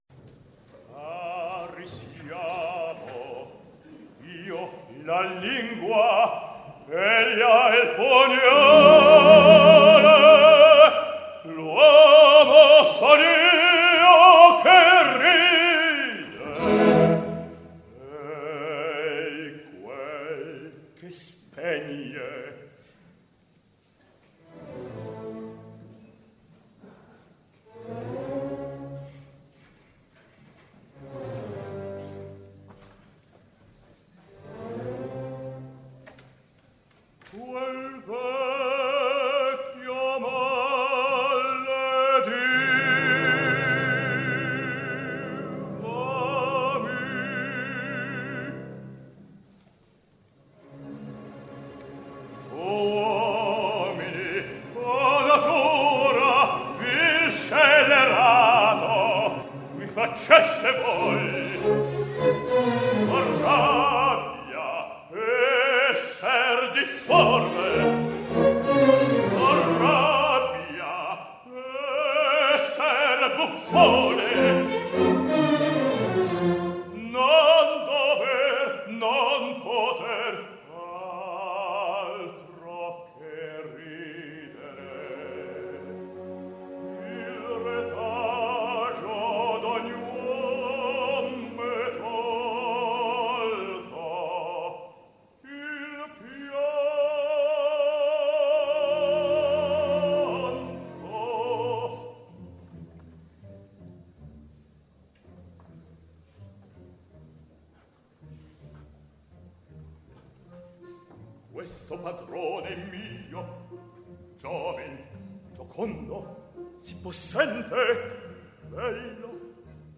baryton
OPERA